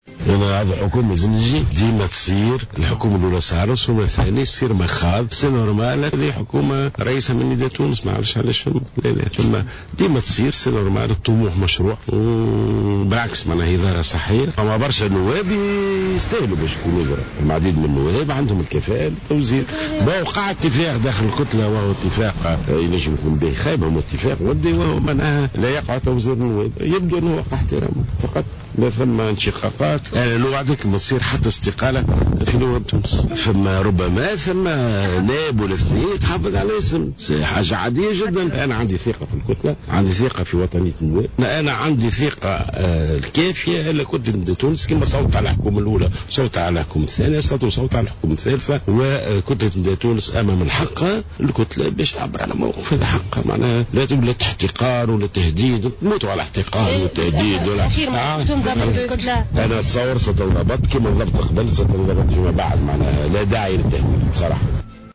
وأضاف جلول في تصريح إعلامي اليوم أن احتراز بعض النواب من حزب نداء تونس على تشكيلة الحكومة أمر طبيعي و"ظاهرة صحية"، وفق تعبيره.